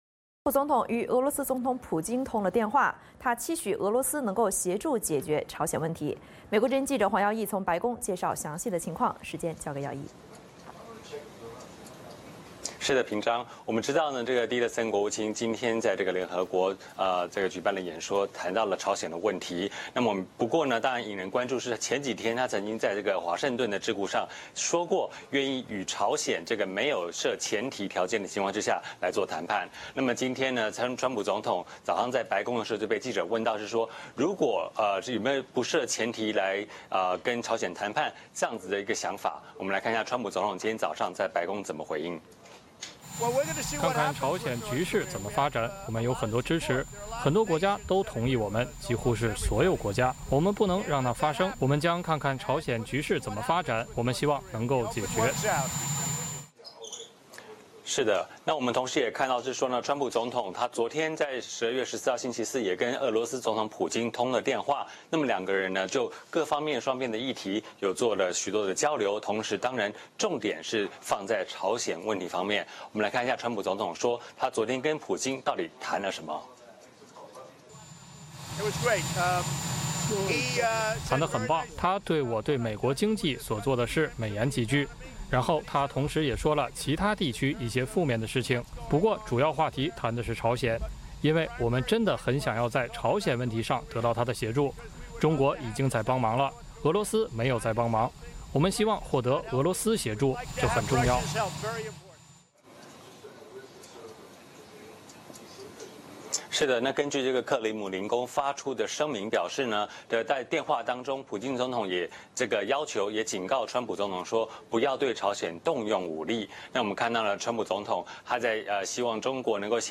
VOA连线：川普感谢普京赞美，希望俄罗斯协助解决朝鲜问题